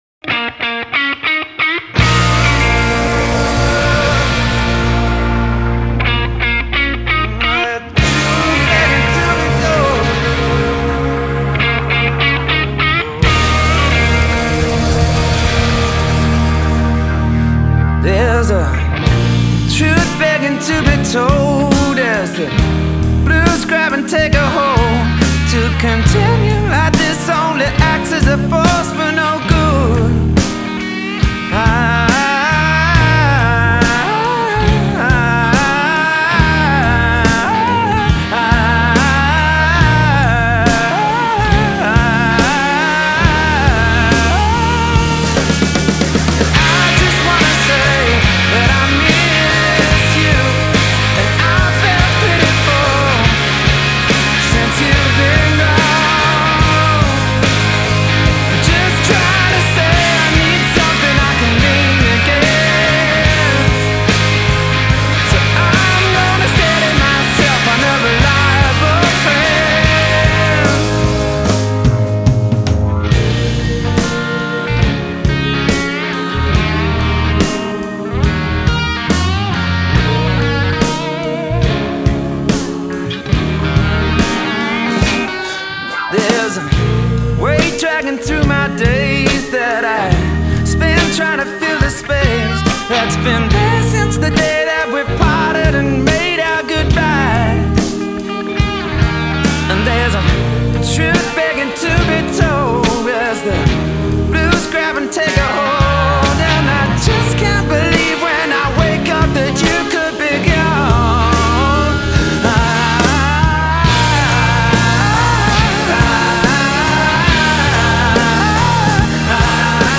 Some truly great rock guitar work on this one.